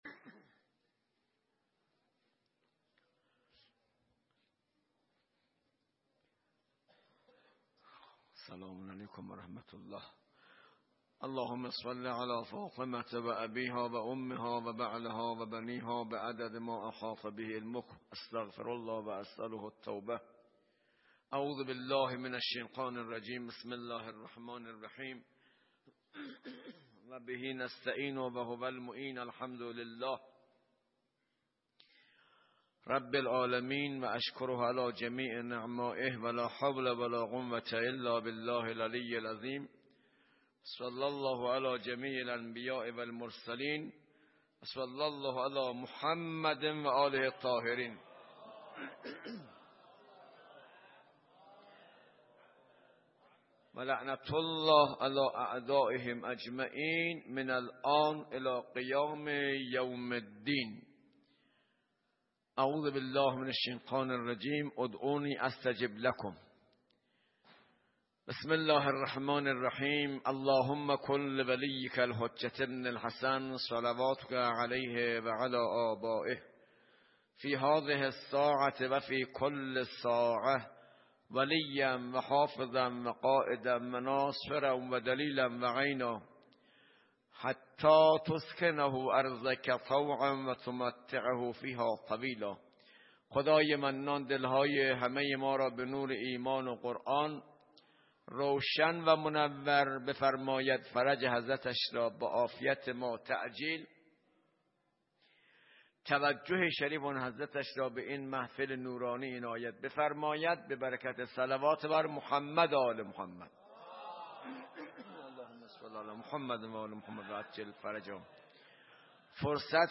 وعظ و خطابه